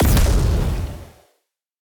Explosion0001.ogg